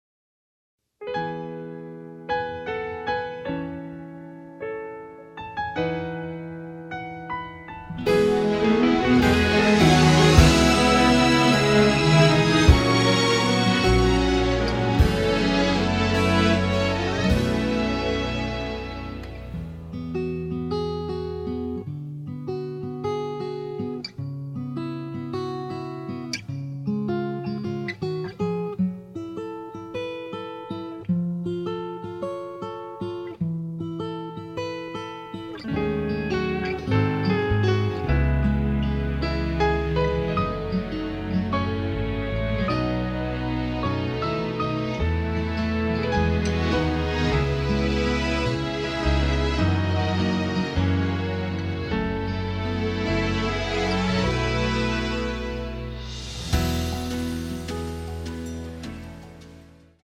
[공식 음원 MR]
앞부분30초, 뒷부분30초씩 편집해서 올려 드리고 있습니다.
중간에 음이 끈어지고 다시 나오는 이유는